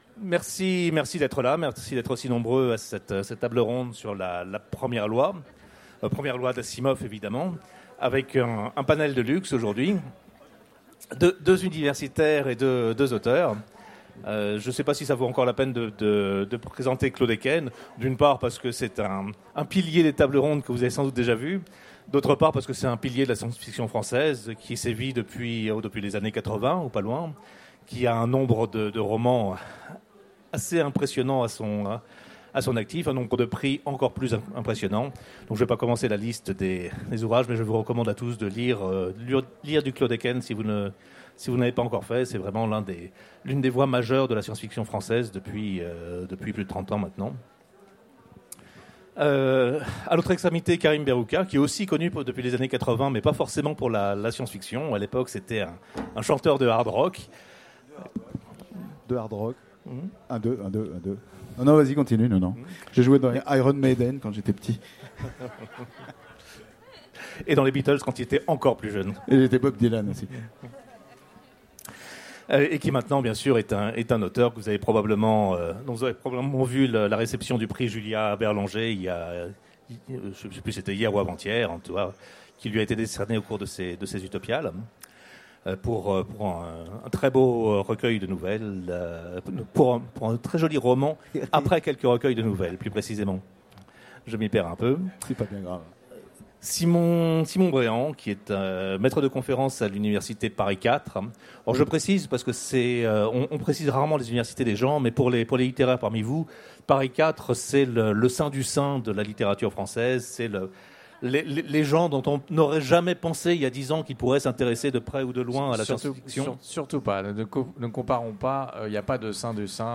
Utopiales 2016 : Conférence Au-delà de la premiere loi